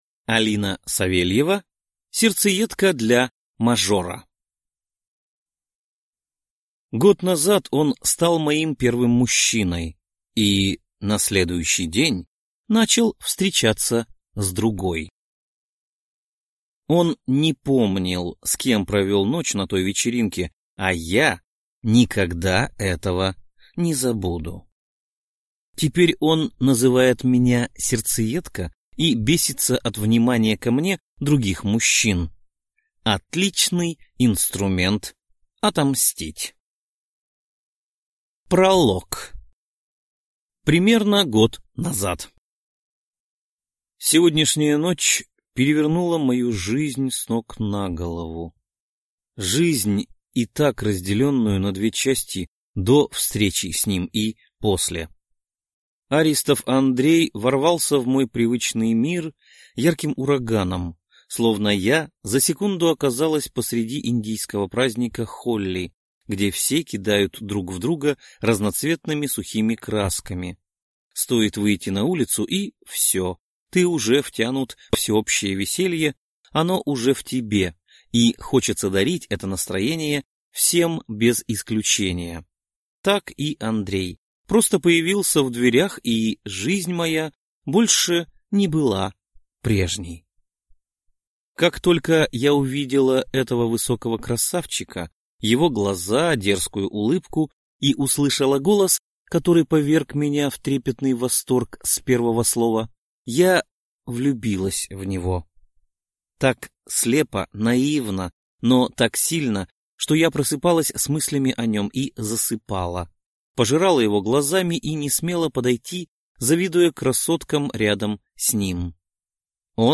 Аудиокнига Сердцеедка для мажора | Библиотека аудиокниг